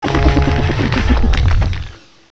sovereignx/sound/direct_sound_samples/cries/revavroom.aif at master
revavroom.aif